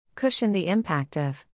発音：/ˈkʊʃən/